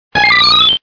Cri de Zarbi dans Pokémon Diamant et Perle.